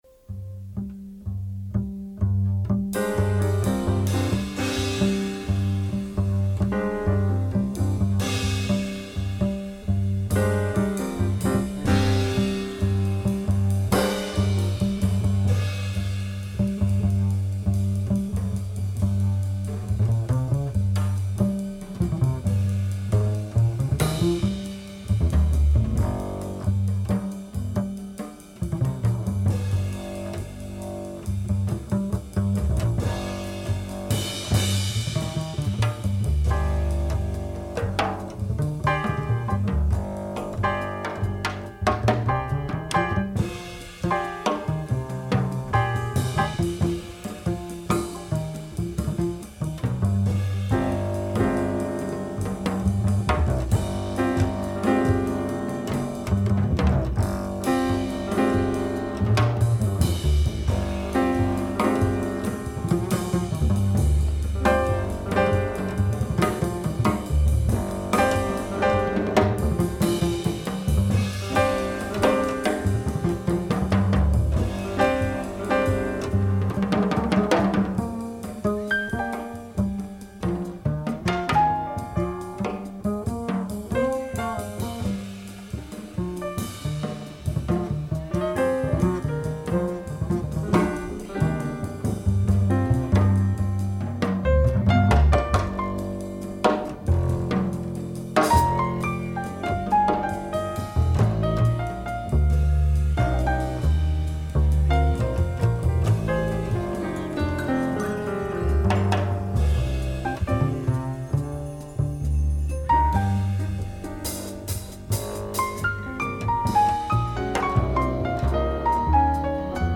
Deep Japanese jazz
bamboo flute